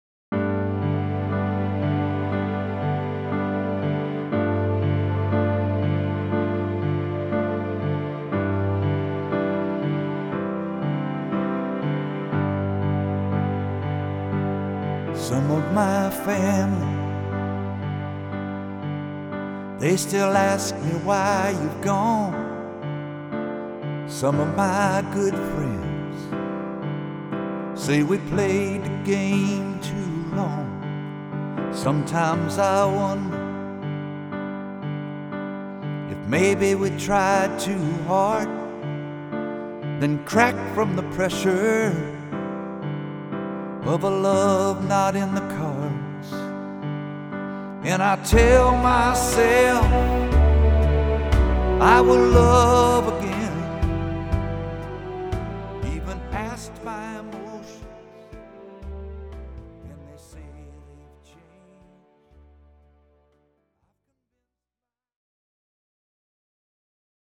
Here are some tracks from the studio that we hope you enjoy.